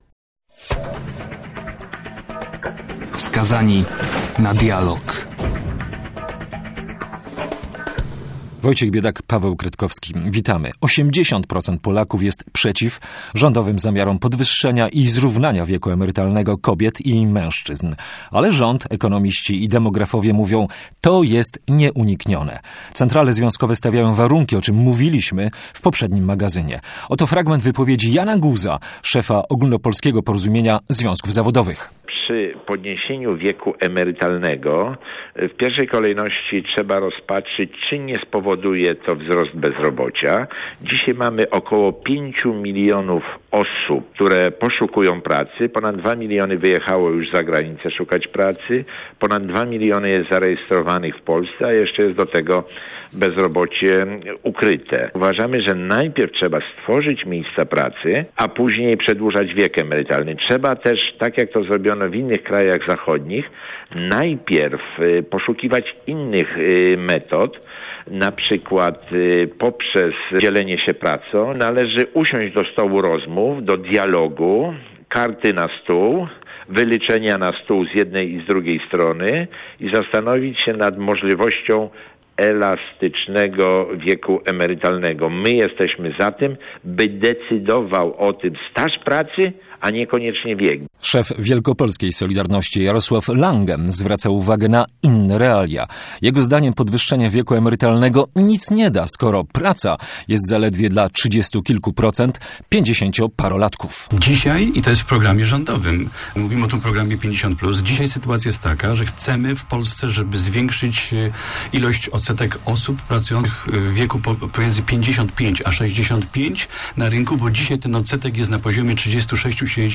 Reportaże - debaty - wywiady.